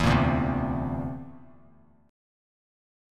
D7sus2#5 chord